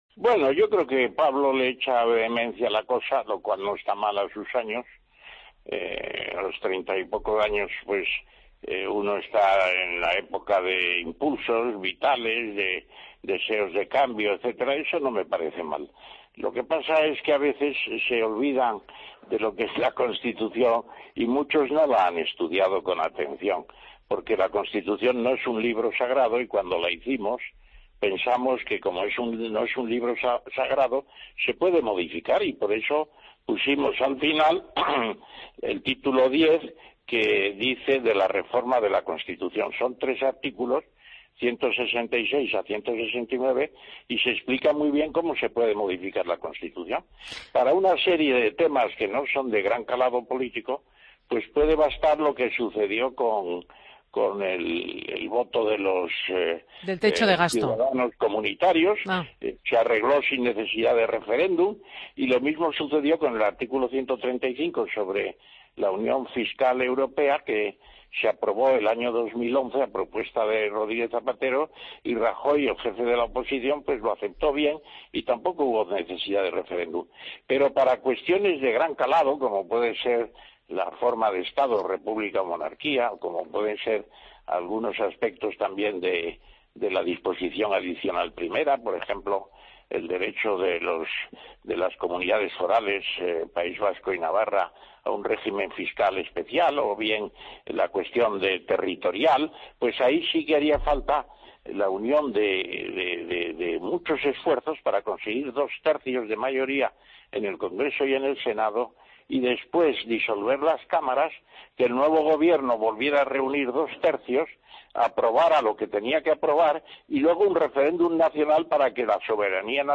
Entrevista a Ramón Tamames en Fin de Semana COPE